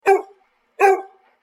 دانلود صدای سگ 3 از ساعد نیوز با لینک مستقیم و کیفیت بالا
جلوه های صوتی